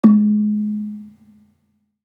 Gambang-G#2-f.wav